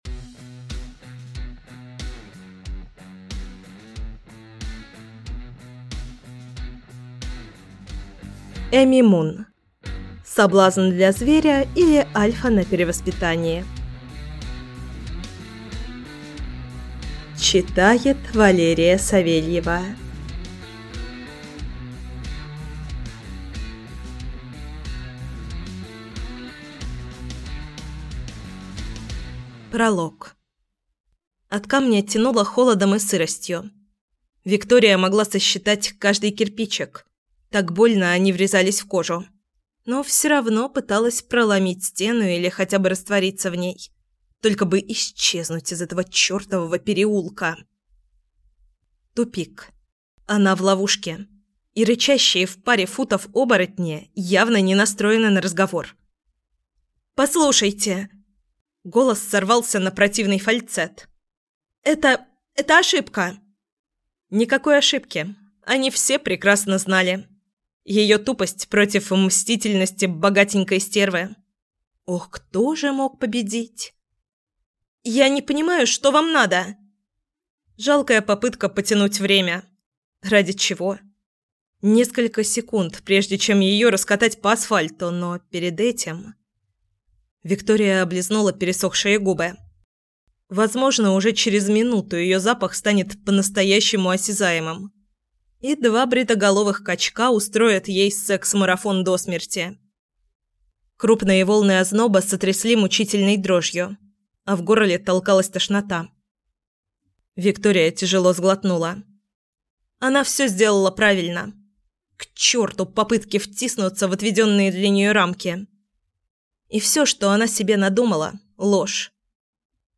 Аудиокнига Соблазн для зверя, или Альфа на перевоспитании | Библиотека аудиокниг